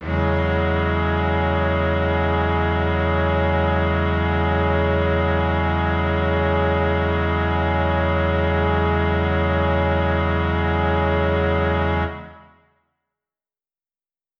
SO_KTron-Cello-E6:9.wav